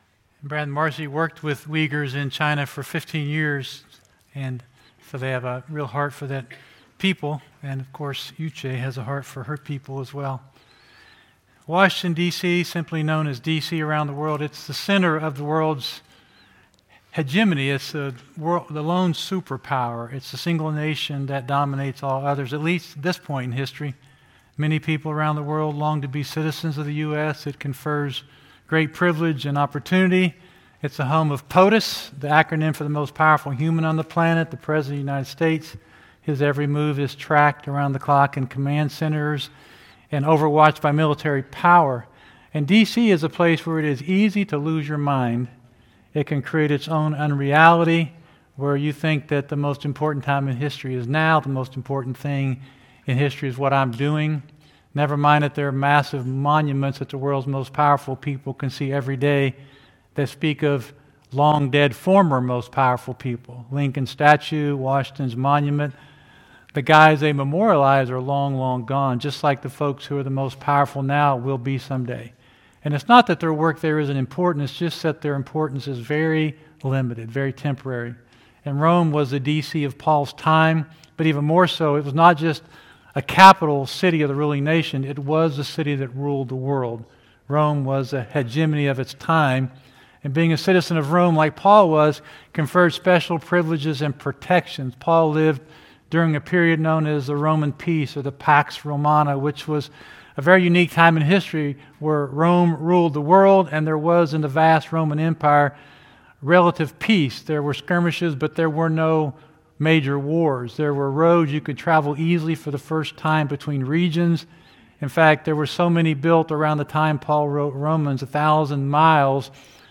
River Community Church Sunday Morning messages